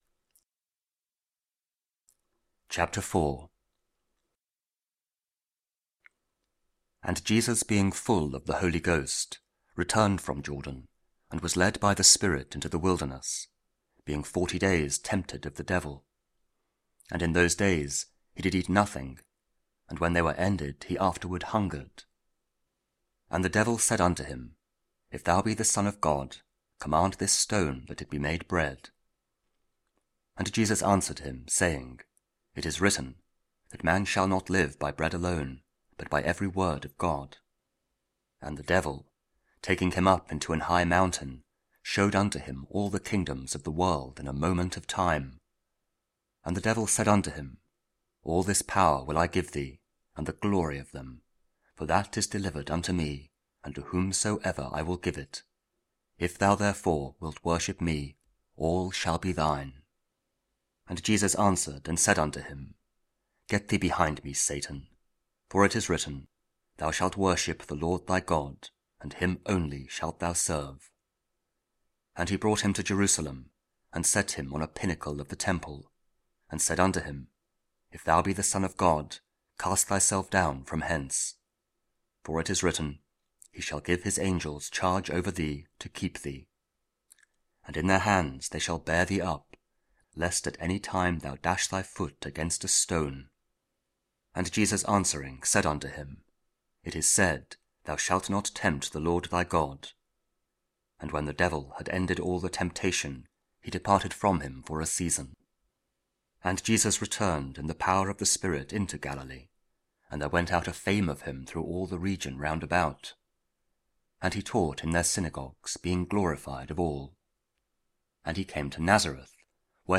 Luke 4: 14-22 – Thursday after Epiphany – also 10 January. (Audio Bible, Spoken Word)